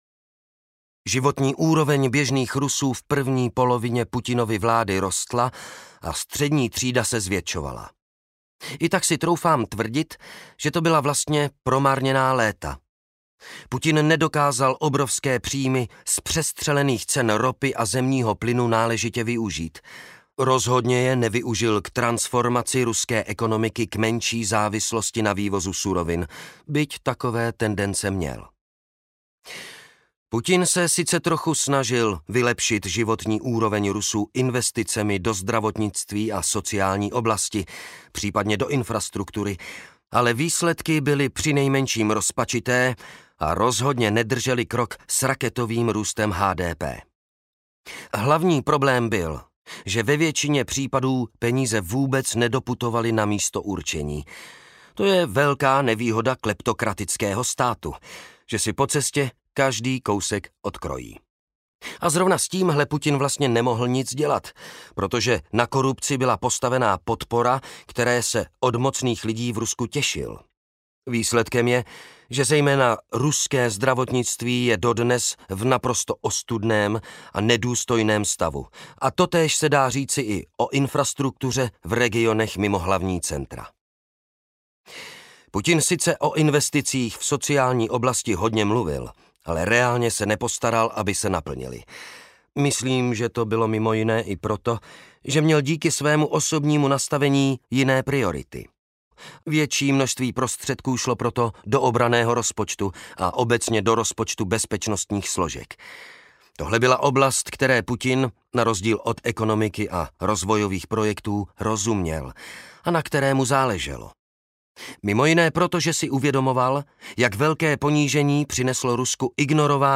Válka na Ukrajině: kontext audiokniha
Ukázka z knihy